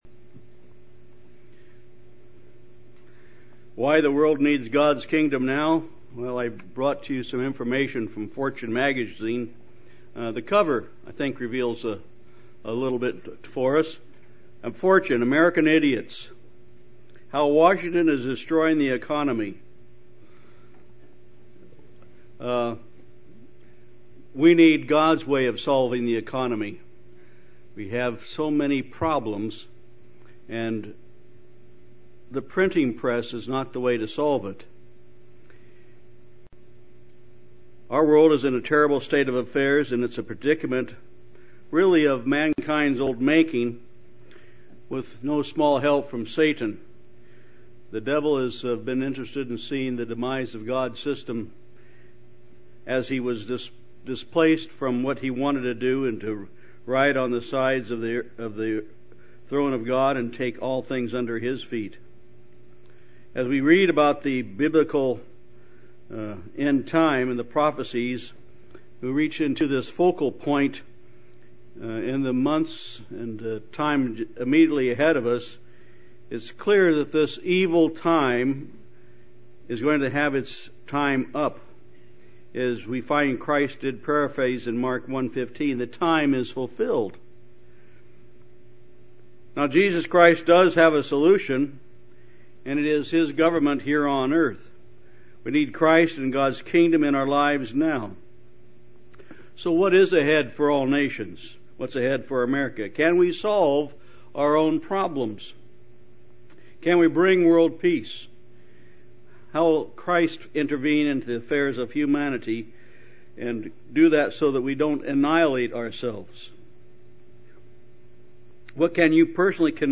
Christ has the solution - the Kingdom of God. Learn more in this Kingdom of God seminar.
Given in Tampa, FL